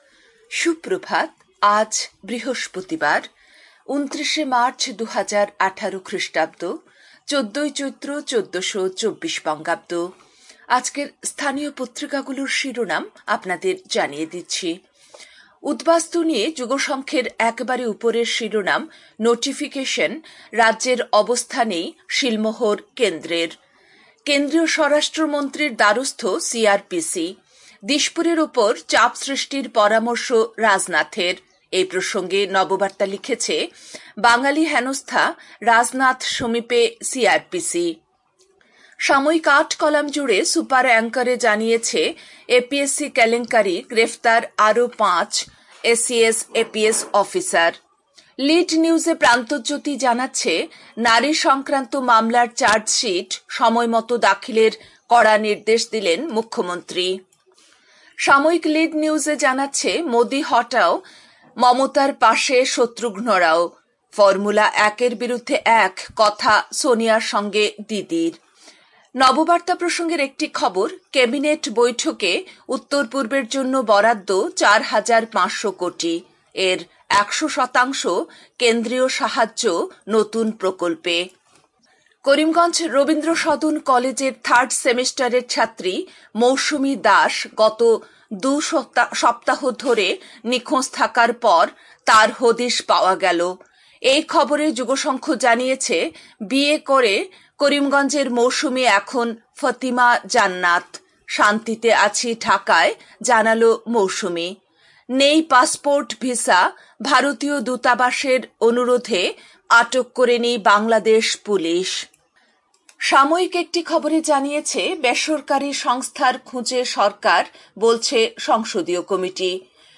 A quick bulletin with all top news.